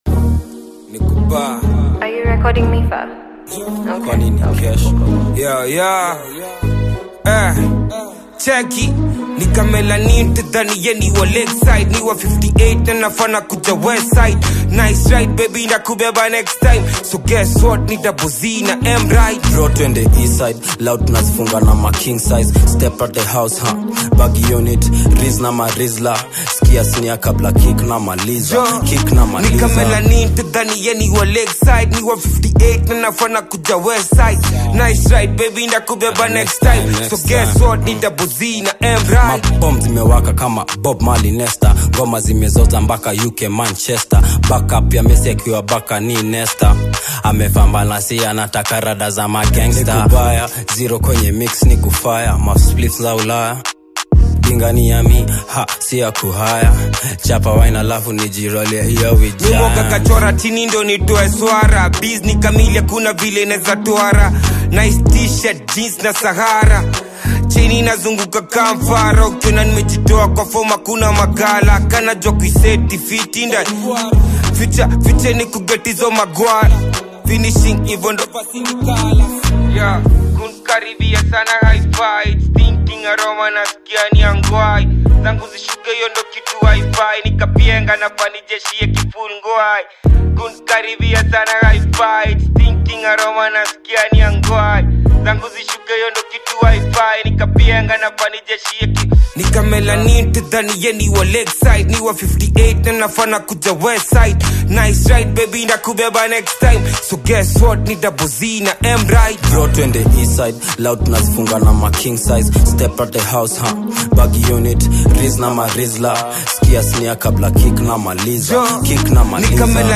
Afrobeat
rhythmic grooves and catchy Swahili/urban verses
energetic delivery